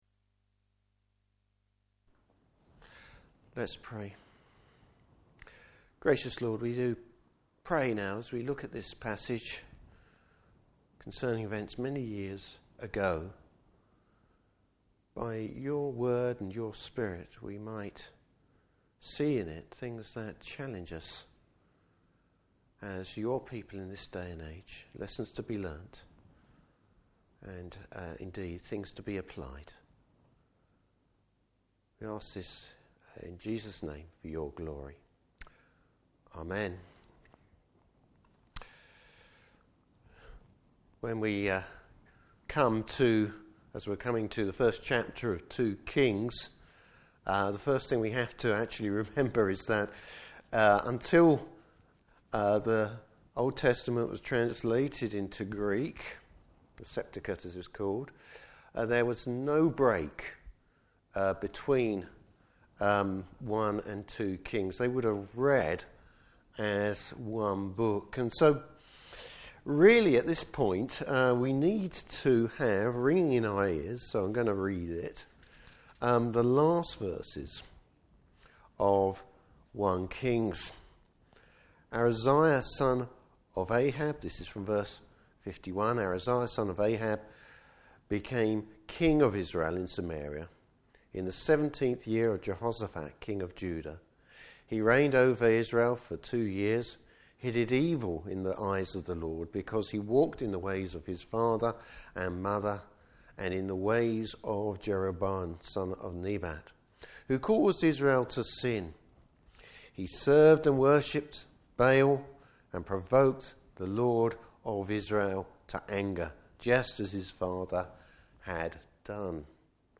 Service Type: Evening Service Bible Text: 2 Kings 1:1-18.